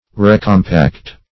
Search Result for " recompact" : The Collaborative International Dictionary of English v.0.48: Recompact \Re`com*pact"\ (-p?kt"), v. t. To compact or join anew.